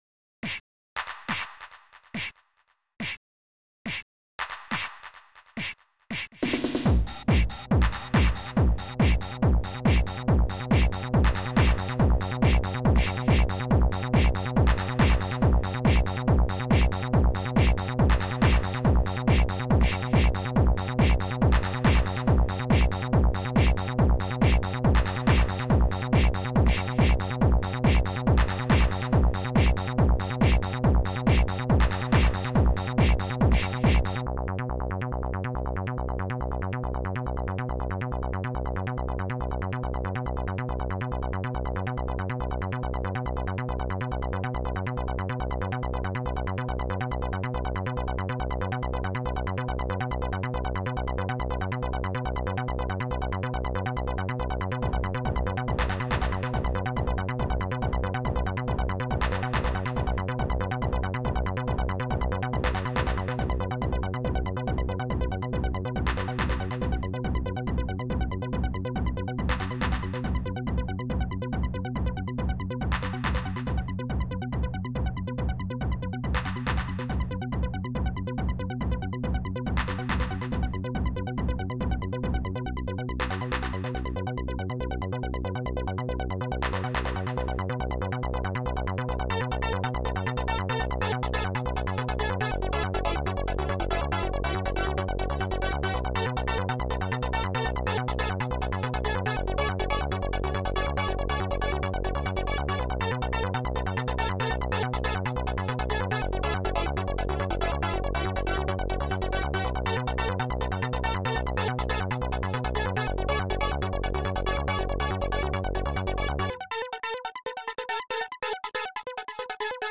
Roland TR-909,
SH-09, Korg MS-20
and Polysix
Length 3:55 - 140 bpm